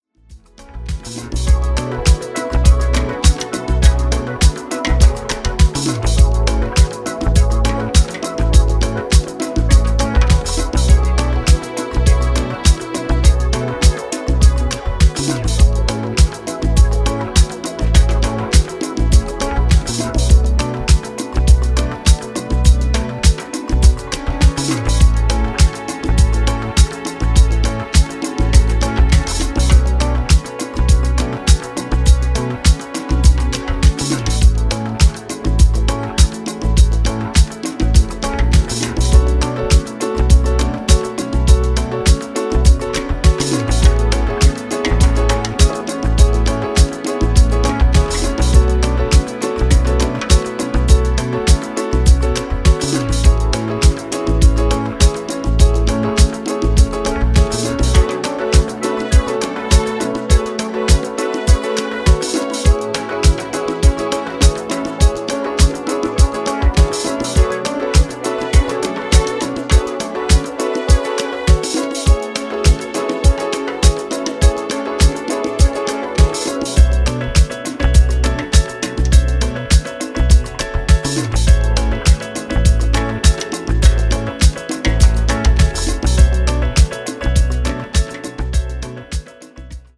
ジャンル(スタイル) HOUSE / BALEARIC